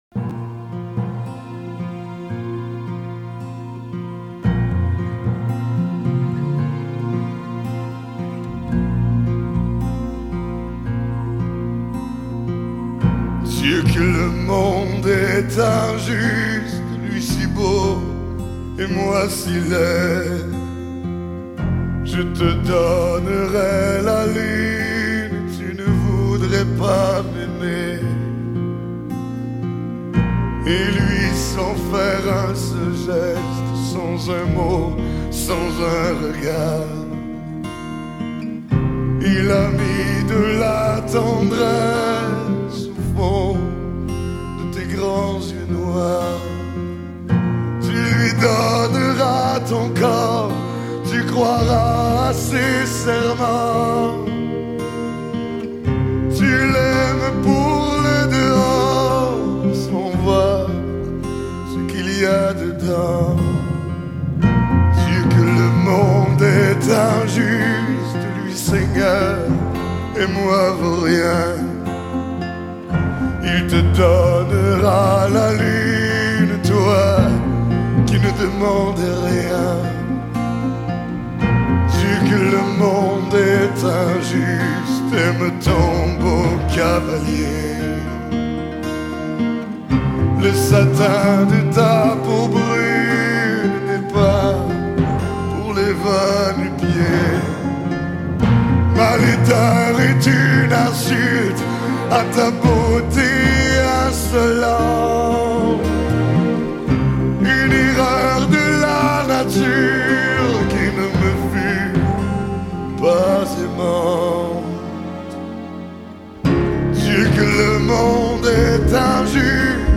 更是用他那特别的嗓音将这份悲凉演绎到极致···